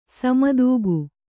♪ sama dūgu